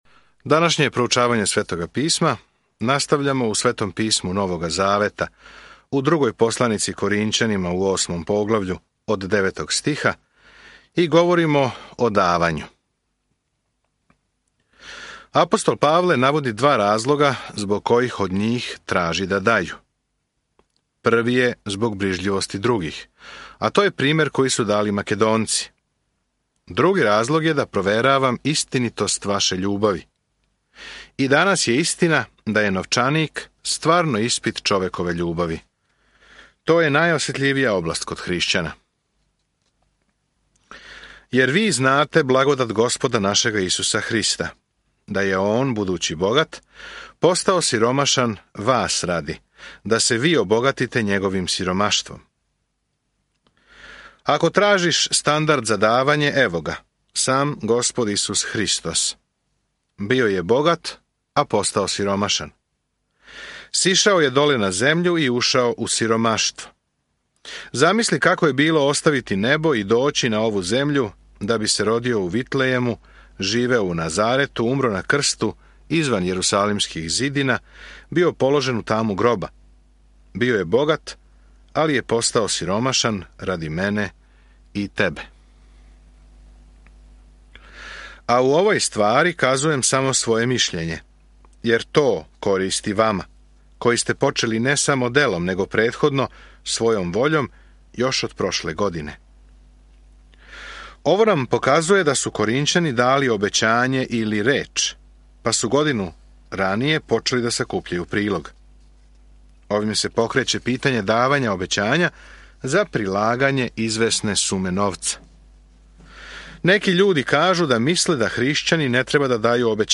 Свакодневно путујте кроз 2 Коринћанима док слушате аудио студију и читате одабране стихове из Божје речи.